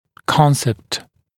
[‘kɔnsept][‘консэпт]концепция, понятие